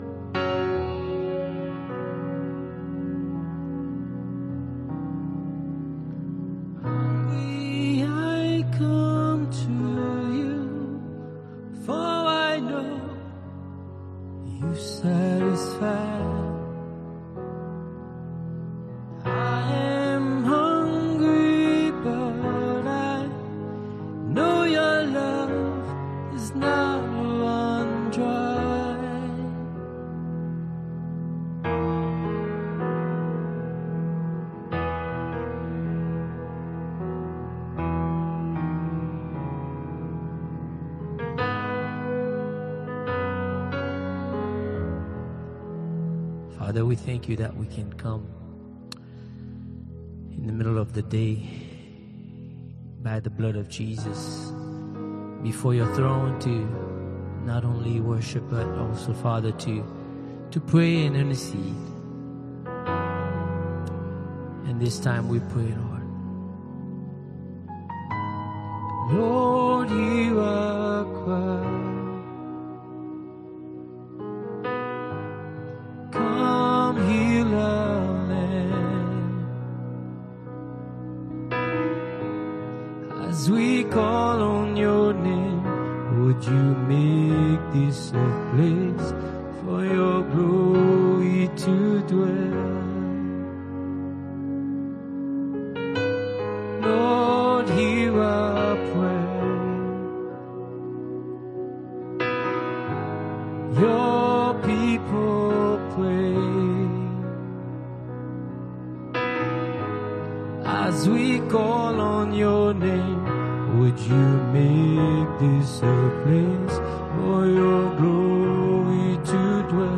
Soaking Prayer and Worship